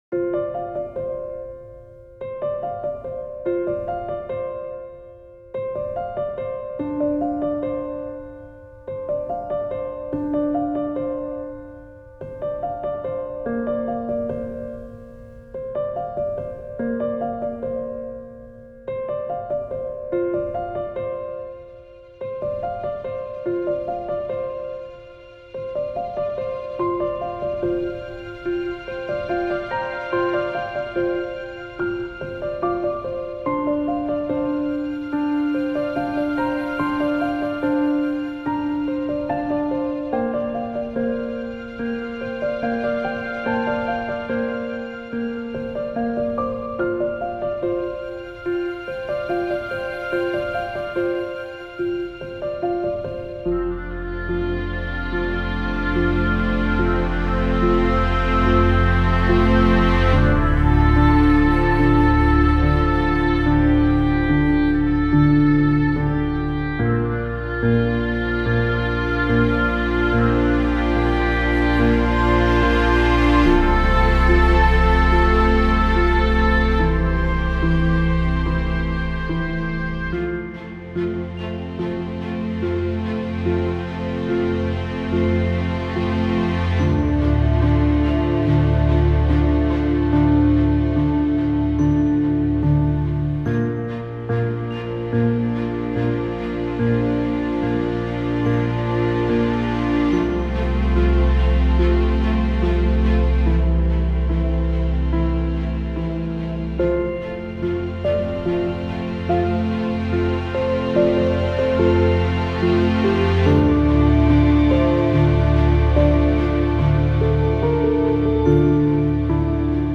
Setting - Relaxing Orchestra